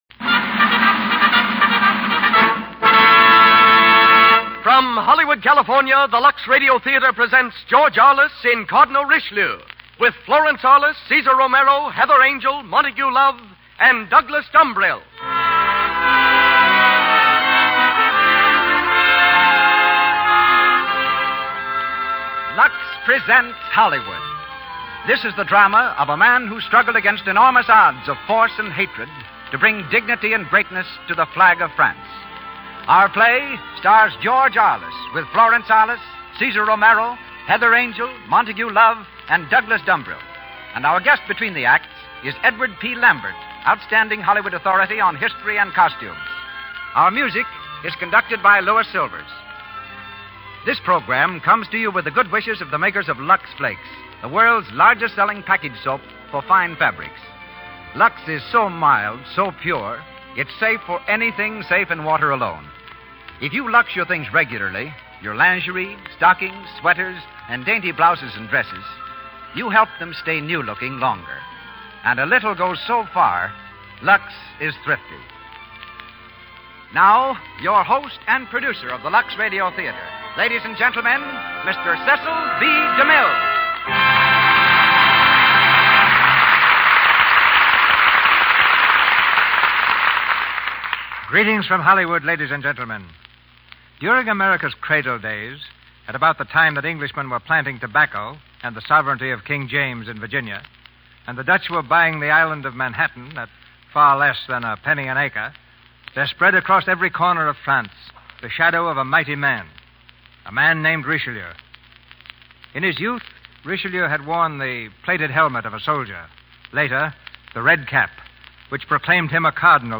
On January 23, 1939, George Arliss stepped before a live audience and a live microphone to broadcast a radio adaptation of his 1935 hit film, CARDINAL RICHELIEU. This prestigious event was one of the highlights of that season’s Lux Radio Theatre, hosted by none other than Cecil B. DeMille.
It is interesting to hear Mr. and Mrs. A exchange harsh words in character rather than the romantic dialogue usually heard in their films.